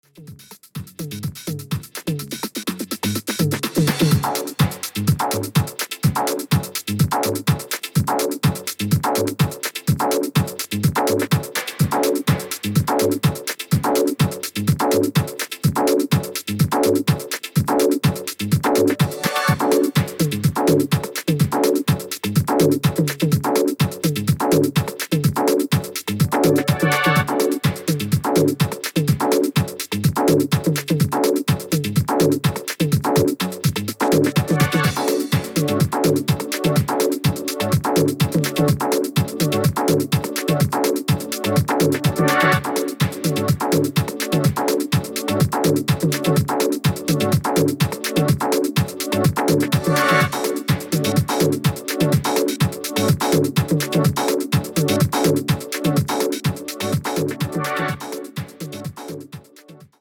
時間軸関係ない長く使えるDeep House 4曲収録12"です。